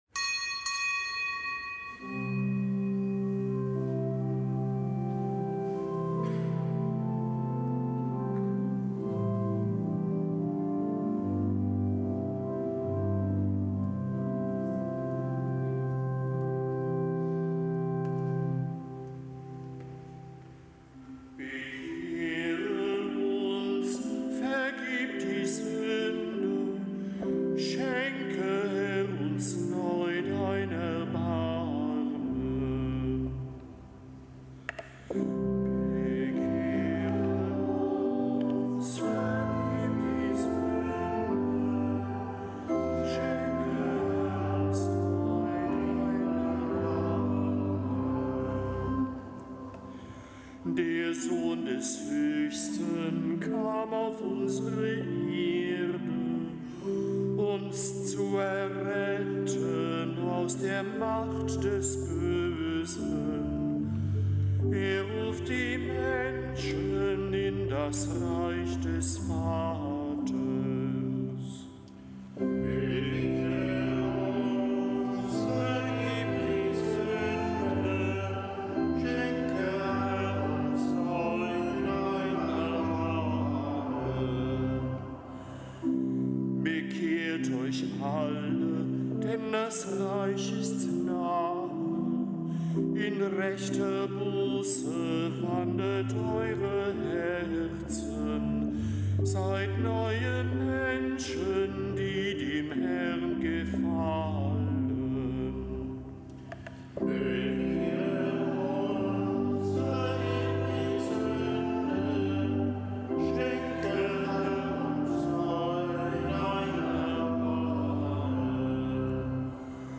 Kapitelsmesse aus dem Kölner Dom am Mittwoch der dritten Fastenwoche. Zelebrant: Weihbischof Dominikus Schwaderlapp.